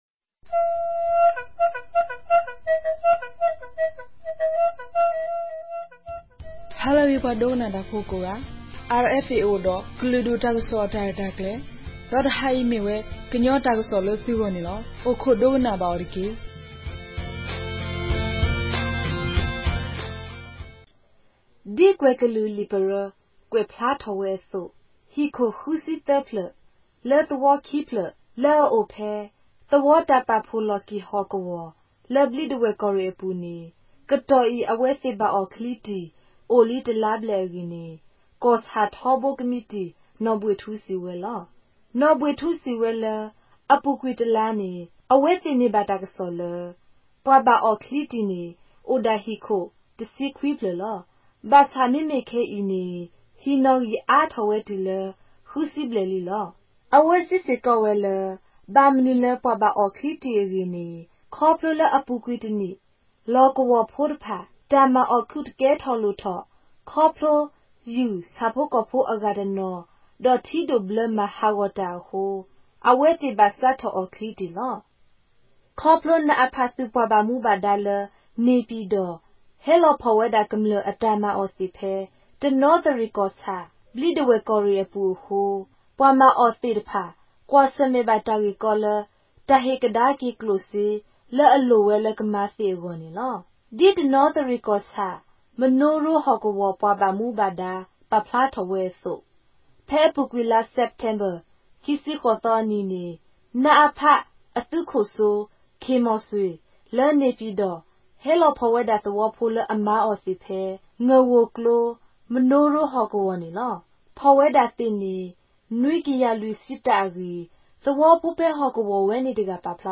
ကရင်ဘာသာ အသံလြင့်အစီအစဉ်မဵား